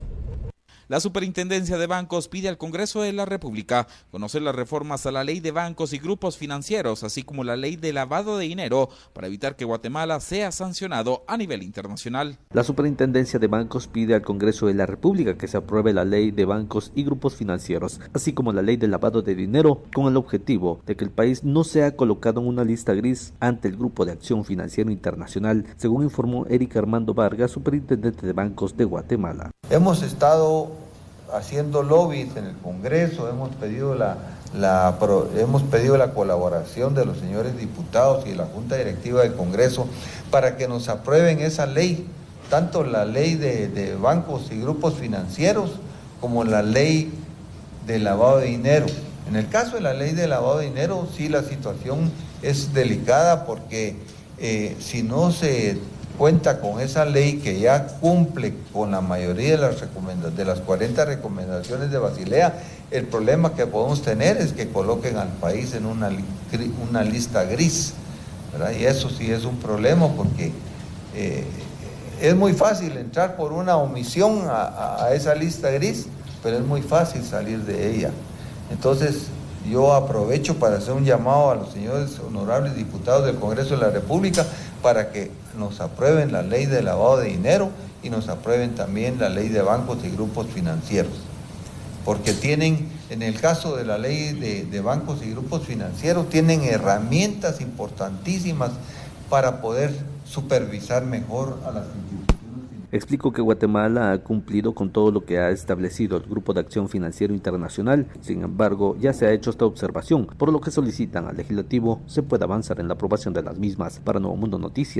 Noticias Iniciativa de Ley 5820